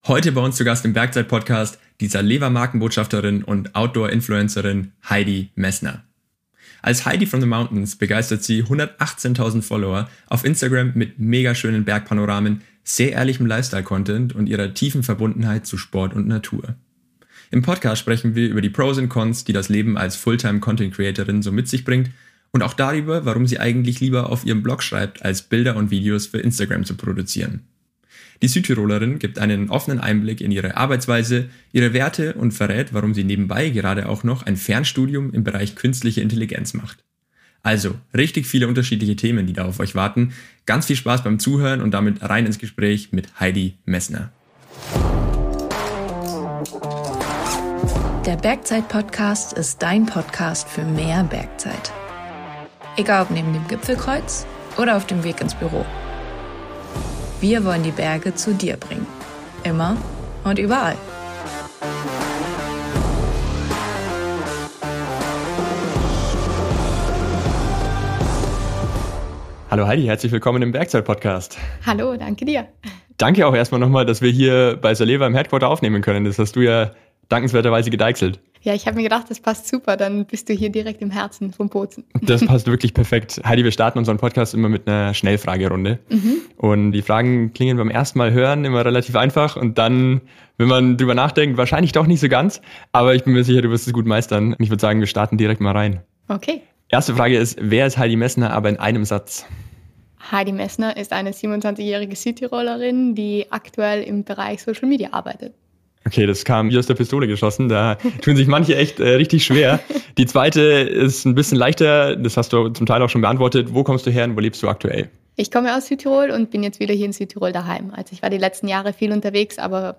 #87 Interview